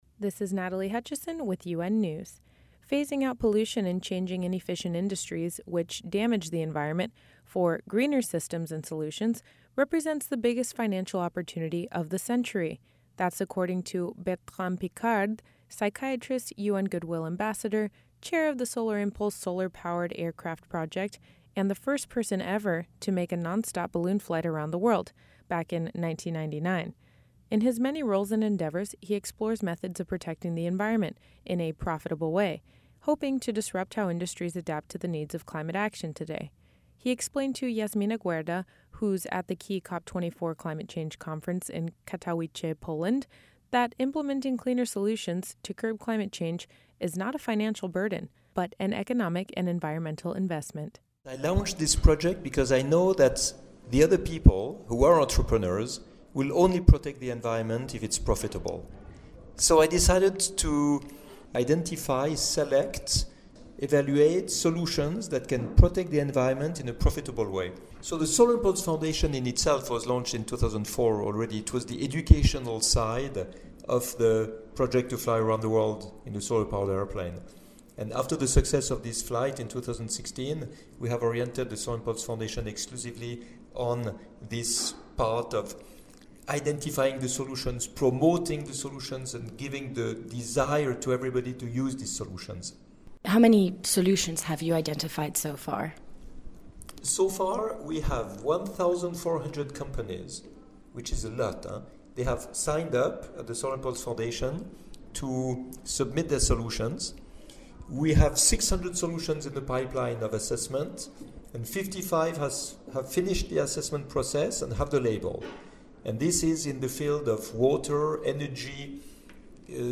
Listen below, to Betrand Piccard talking at COP 24 Climate Conference on renewable energy projects and how they can be balanced with the development needs of countries.